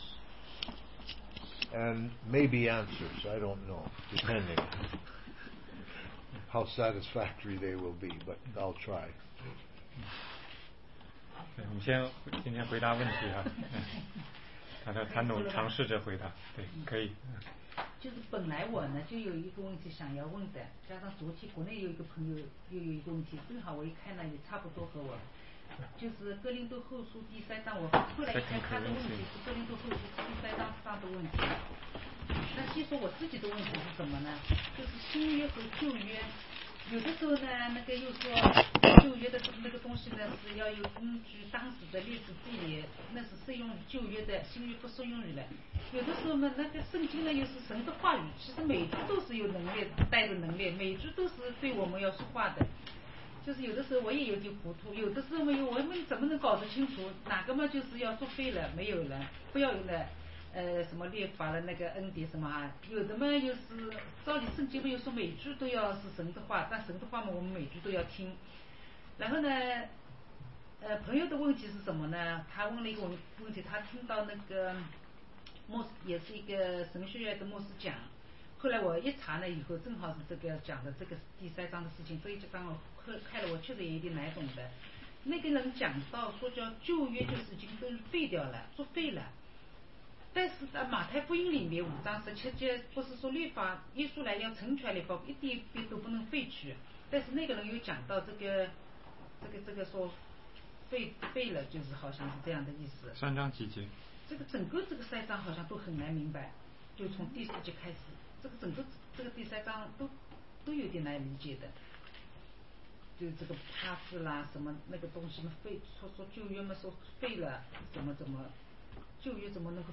16街讲道录音 - 旧约圣经对新约时期的基督徒有什么意义
答疑课程